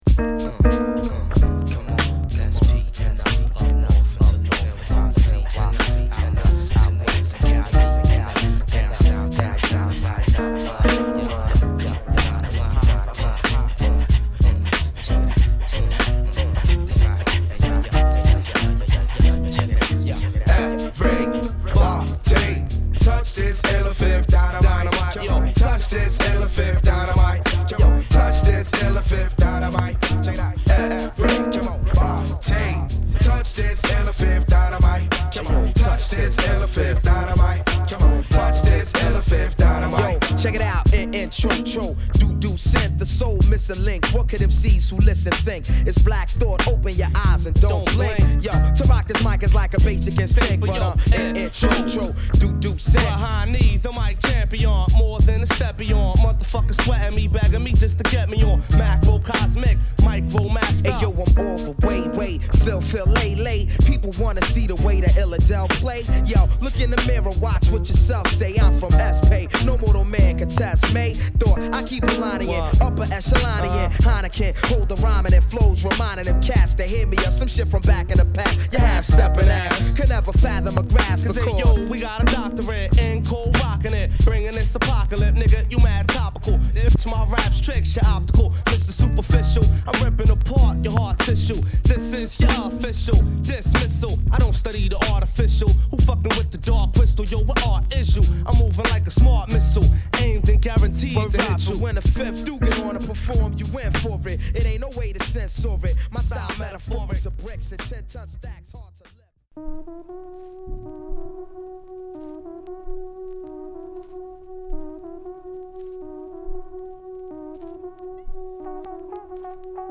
ソウルフルでタイトでスムースなコンシャス・ヒップホップ名作を中心に詰め込んだ充実の2LP。